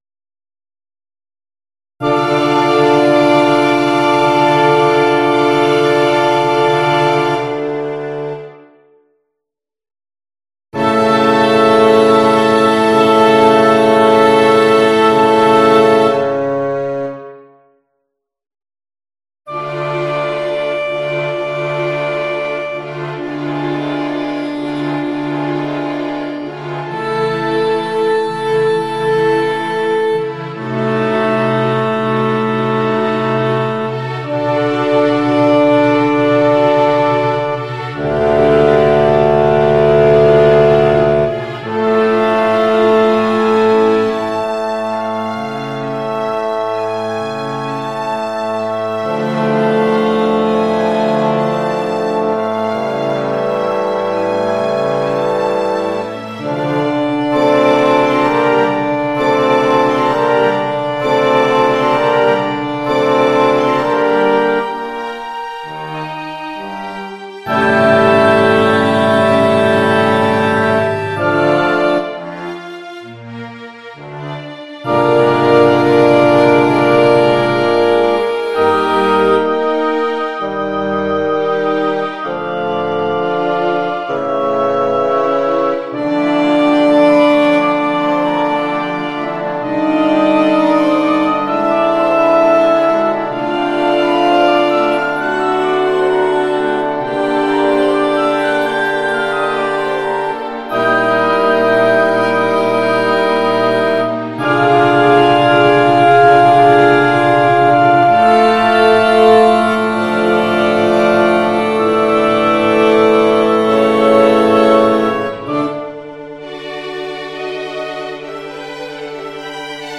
05/03/02 ティンパニの入力ミスを訂正しました。
管弦楽はGarritan Personal Orchestra5(VST)を使ってMP3形式で保存したものです。